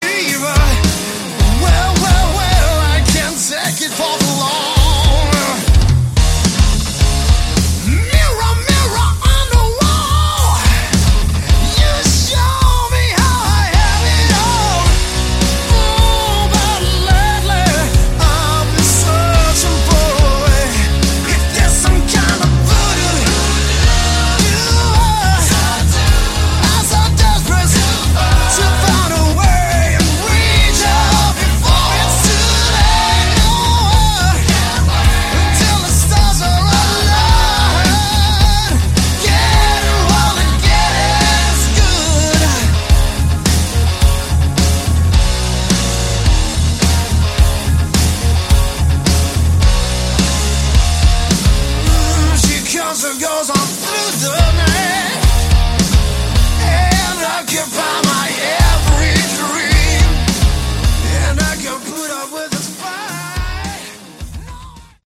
Category: Melodic Rock
keyboards, vocals
guitar, keyboards
bass
drums